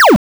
laser_b.wav